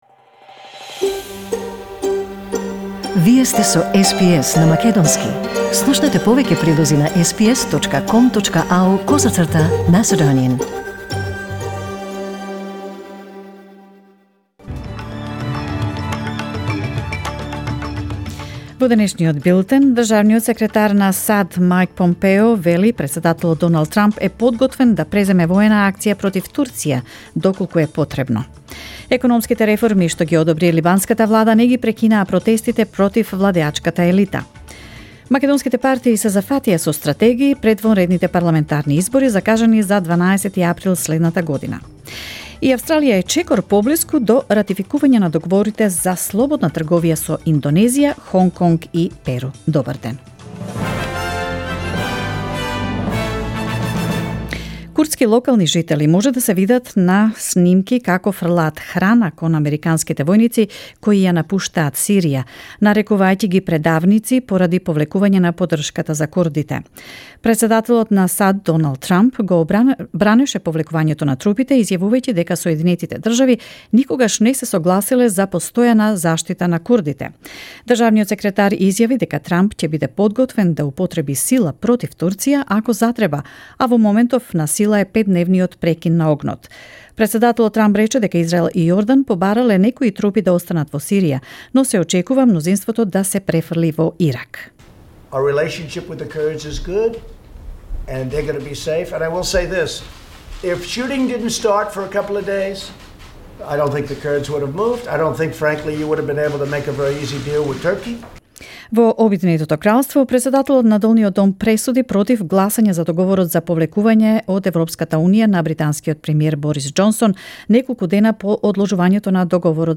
SBS News in Macedonian 22 October 2019